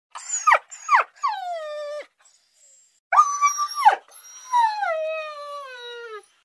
Звуки скулящей собаки
Пёс тоскует в одиночестве